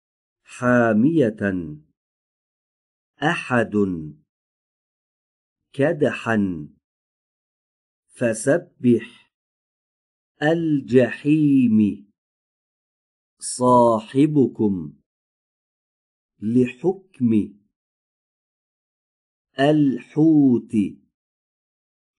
🔸ابتدا به تلفظ حرف «ح» در این کلمات گوش فرا دهید و سپس آنها را تکرار کنید.